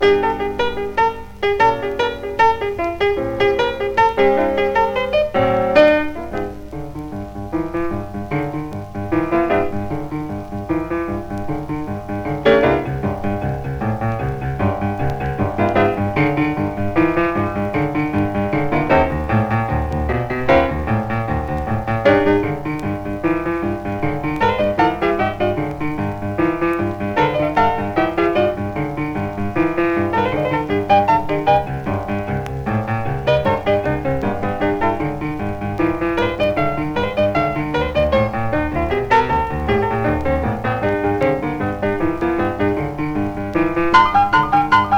Jazz, Swing, Boogie Woogie　USA　12inchレコード　33rpm　Mono